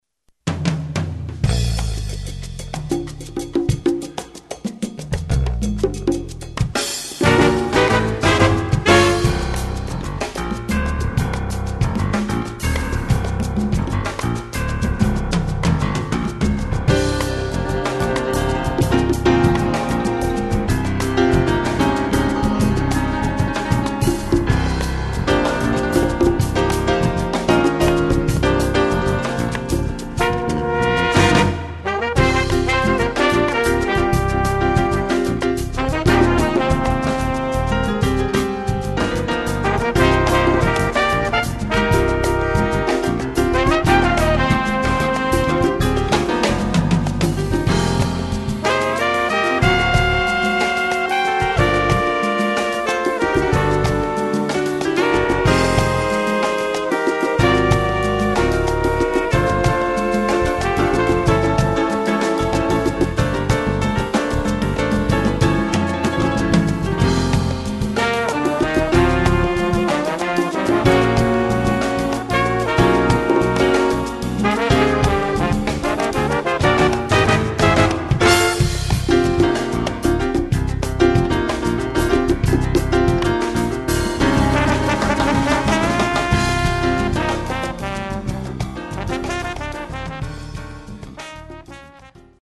Category: Combo
Style: bembe (6/8)
Solos: open, percussion